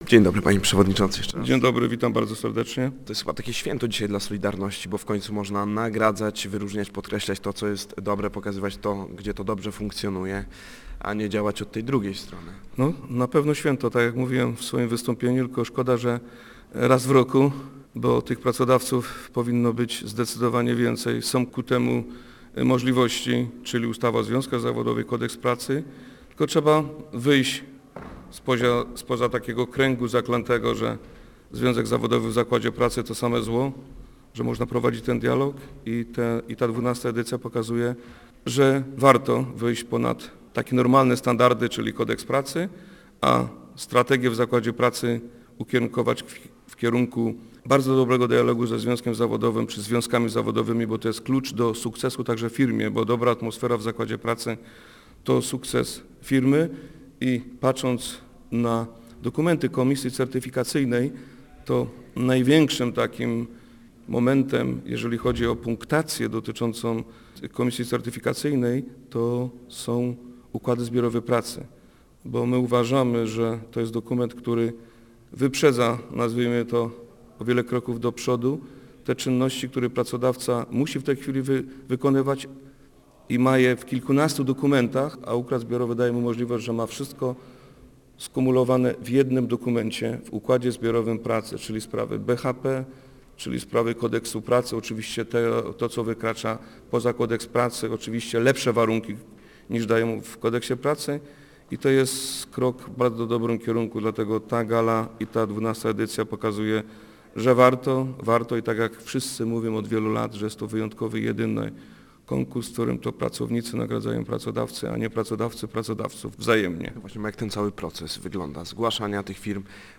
Gościem dnia Radia Gdańsk był Piotr Duda, przewodniczący NSZZ „Solidarność”.